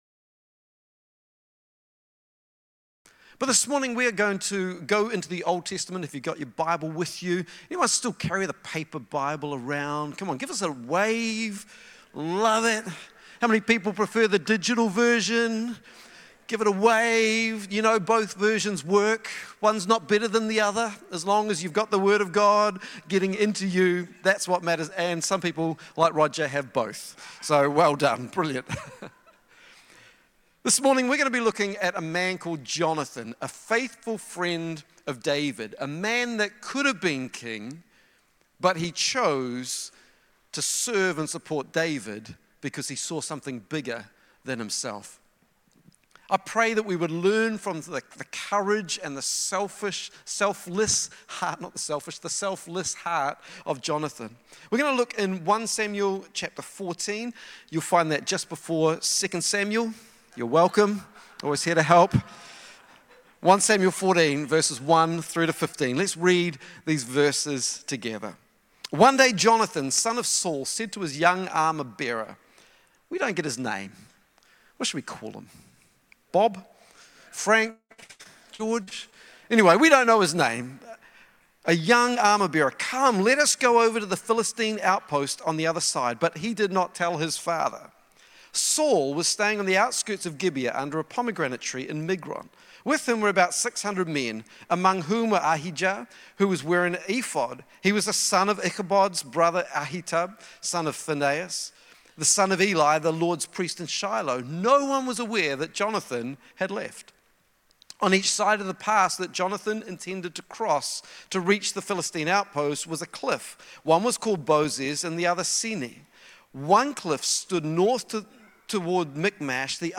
Sunday Messages Front Foot Faith